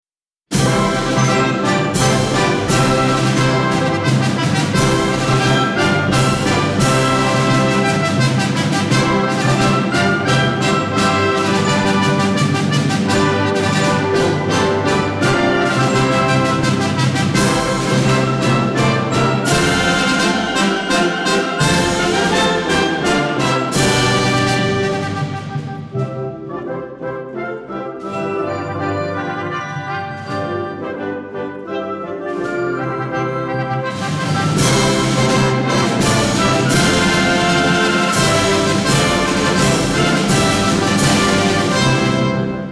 Тогда послушайте оркестровое исполнение, и вы поймёте, почему «Гром победы» по праву можно считать настоящим имперским гимном!
grom_pobedy-instr.mp3